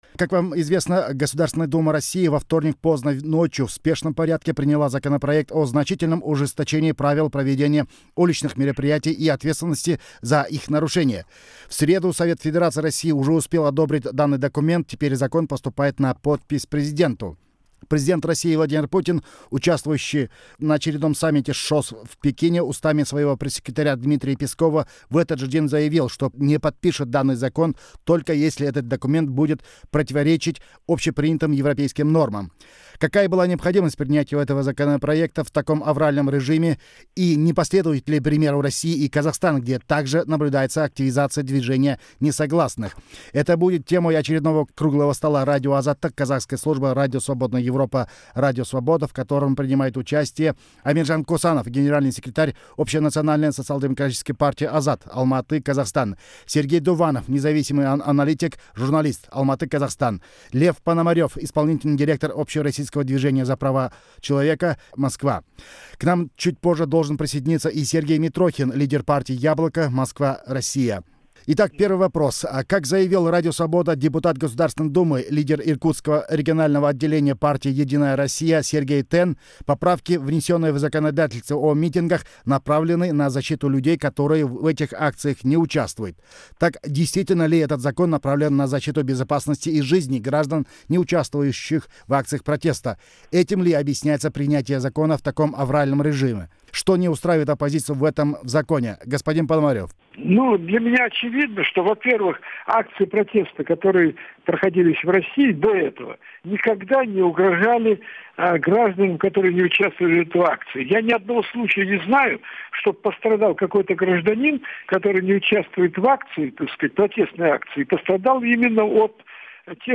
Запись круглого стола